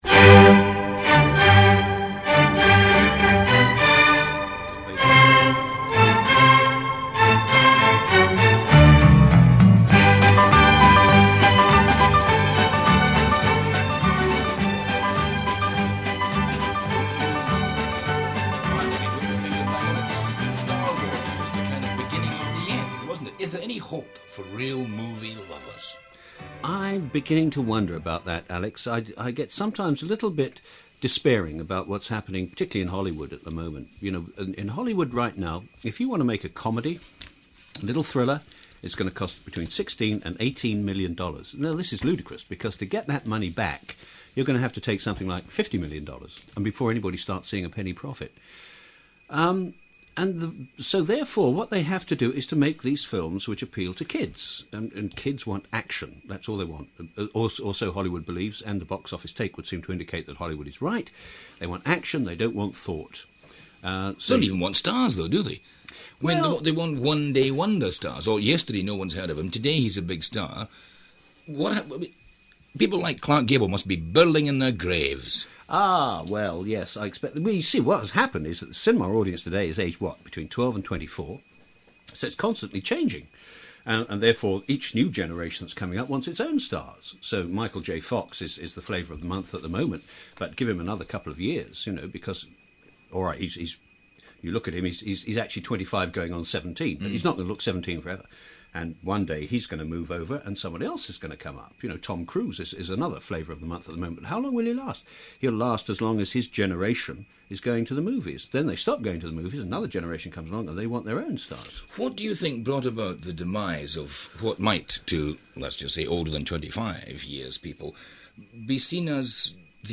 speaking with Barry Norman about the release of his book '100 Best Authors of the Century' on Clyde 2 - Oct 1992